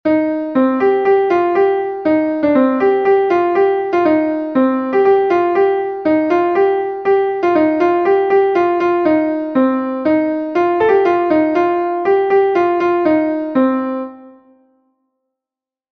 Ton Bale Kamorzh is a Bale from Brittany